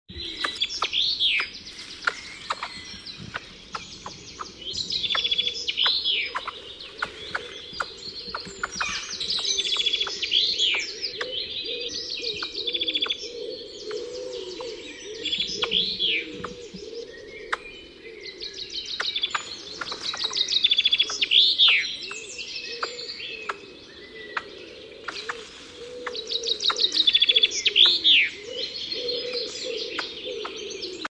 Звуки белки
В лесной чаще